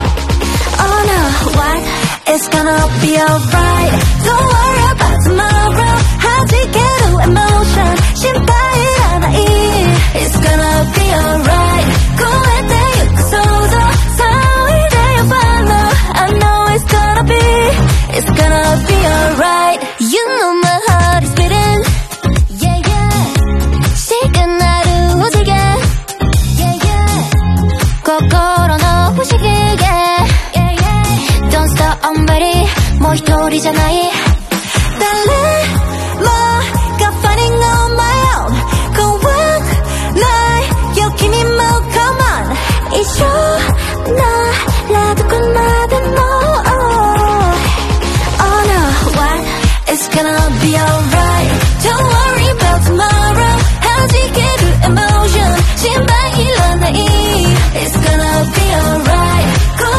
Upcoming K-Pop songs (DELAYED VERSIONS)